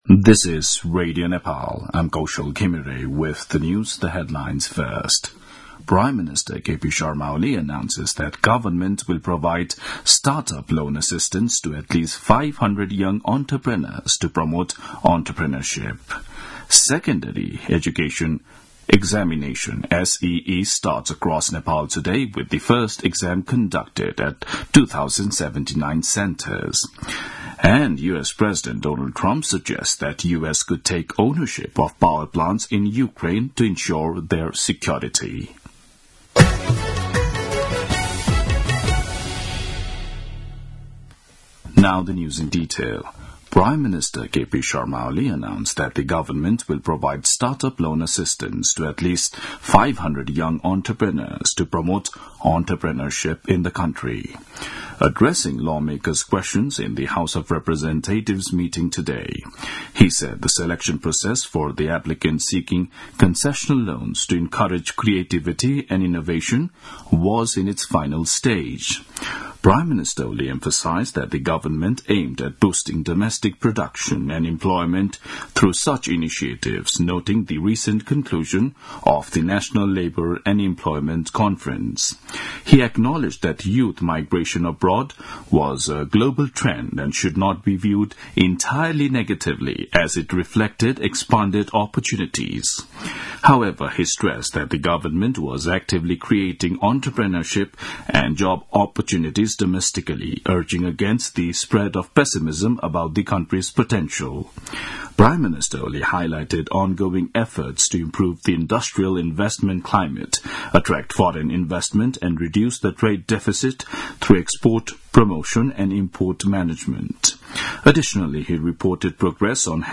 दिउँसो २ बजेको अङ्ग्रेजी समाचार : ७ चैत , २०८१